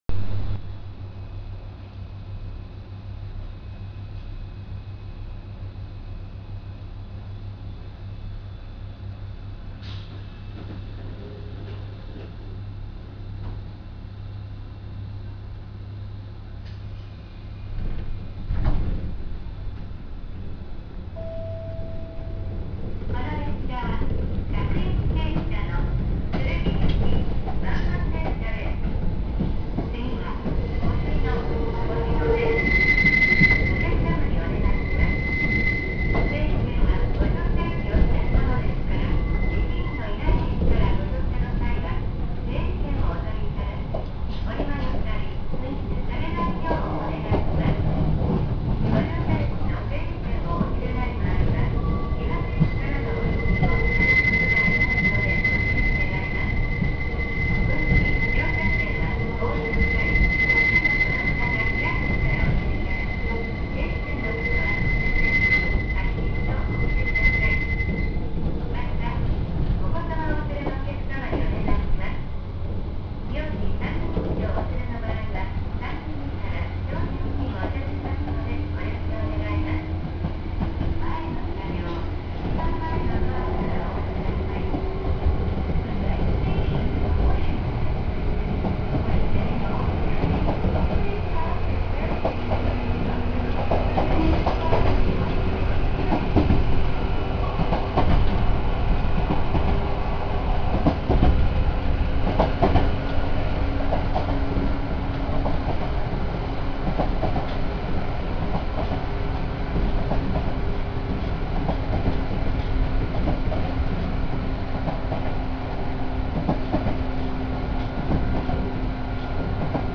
〜車両の音〜
・7000系走行音
【石川線】新西金沢→押野（2分46秒：904KB）
収録は7100形の7102Fですが、基本どの形式でも走行音は変わりません。7000系統時から同じモーター音だとは思うのですが、降圧改造が施されているので音の聞こえ方は違うのかも…。